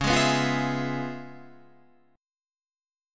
C#7#9 Chord